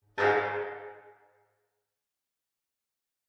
2. (Reverb)
rev.wav